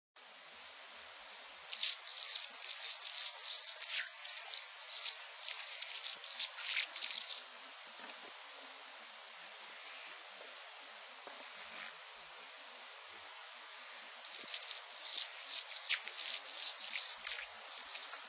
Anna's Hummingbird
Bird Sound
Song a buzzy, scratchy series of squeaking phrases. Also makes chip notes and a twitter.
Anna'sHummingbird.mp3